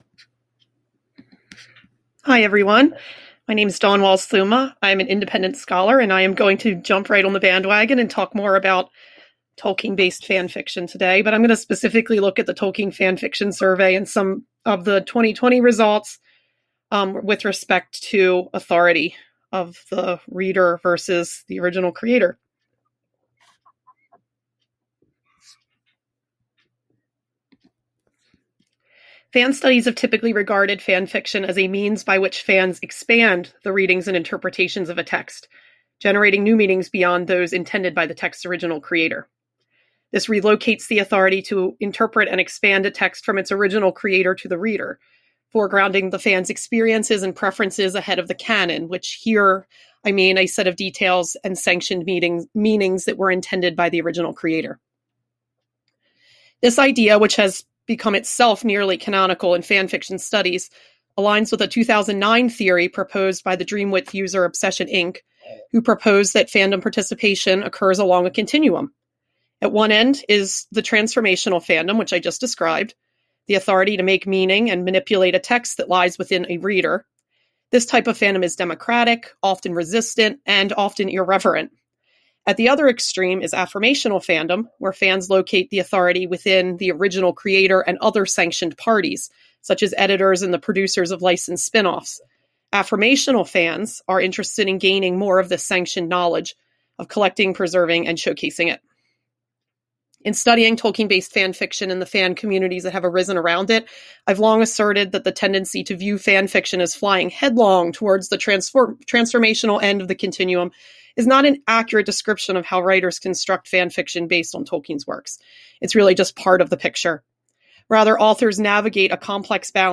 This paper was presented as part of the Roundtable on Tolkien Reception Studies at the Popular Culture Association conference on 2 June 2021.